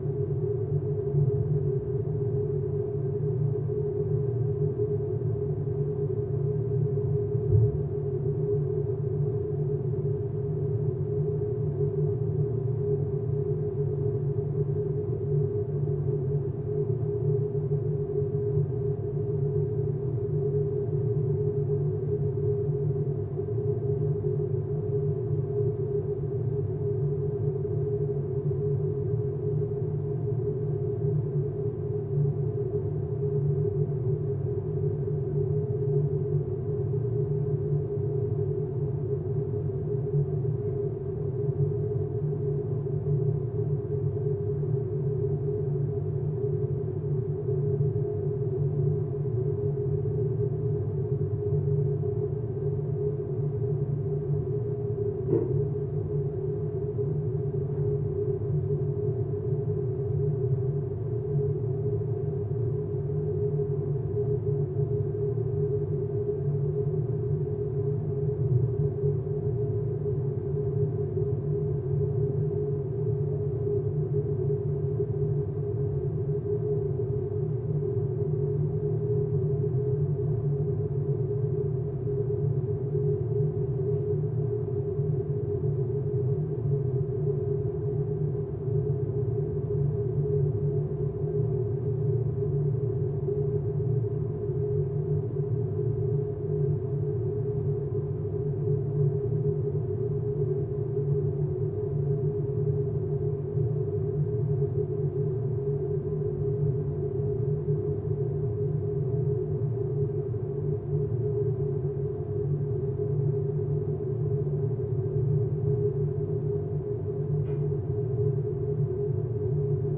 ambience.wav